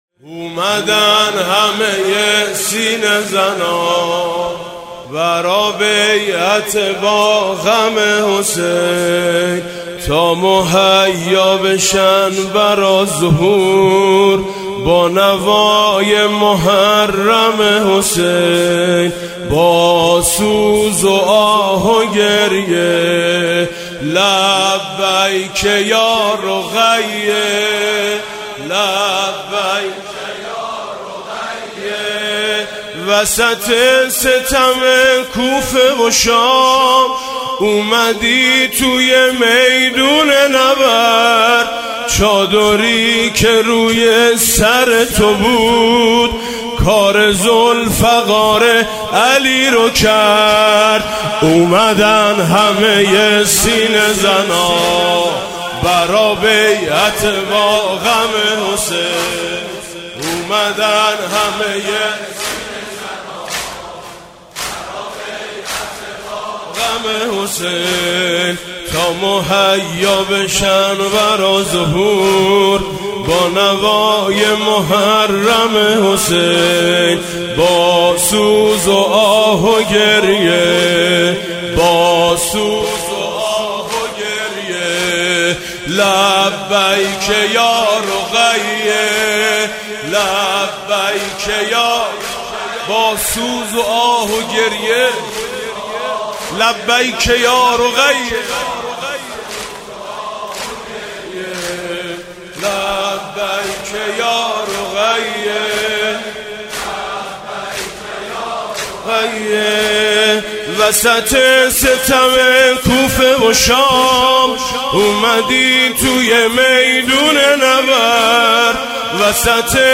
گلچین مداحی شب سوم محرم ۹۸ با نوای میثم مطیعی
فایل صوتی گلچین مداحی شب سوم محرم ۹۸ با نوای مداح اهل بیت(ع) حاج میثم مطیعی در اینجا قابل دریافت است.